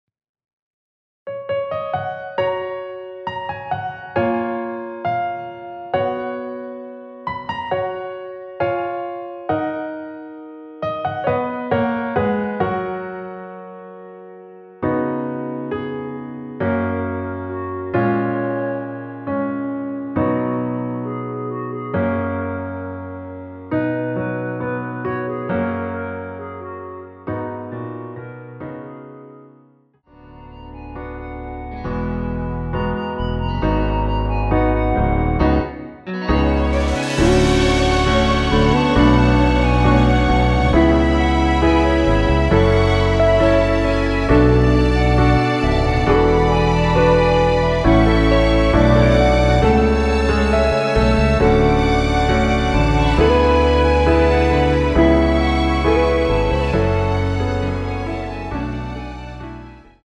원키에서(+4)올린 멜로디 포함된 MR입니다.(미리듣기 확인)
F#
앞부분30초, 뒷부분30초씩 편집해서 올려 드리고 있습니다.
중간에 음이 끈어지고 다시 나오는 이유는